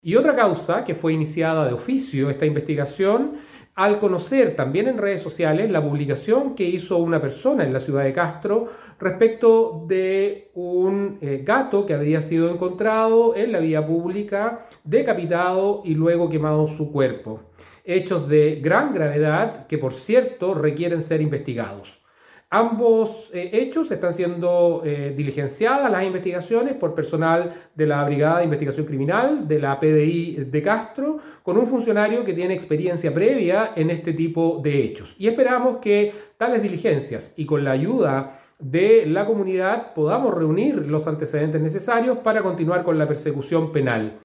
En un segundo caso, se trata de un gato que fue encontrado en la calle en la ciudad de Castro, decapitado y quemado, según relató el fiscal Enrique Canales, quien dijo que estos casos serán llevados adelante por funcionarios de la PDI, encabezados por uno de los integrantes de la policía civil con experiencia en este tipo de delitos.
06-FISCAL-CANALES-MALTRATO-ANIMAL-2.mp3